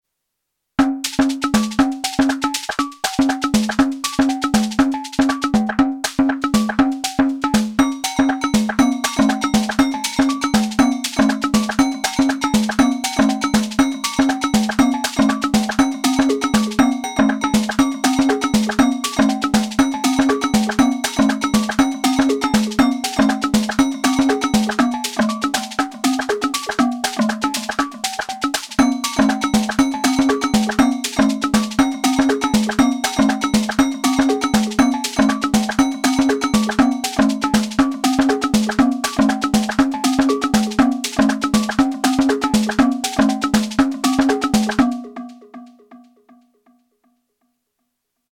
Here’s some ‘latin percussion’ straight from the Syntakt.
The hats and shakers are snares :nerd_face: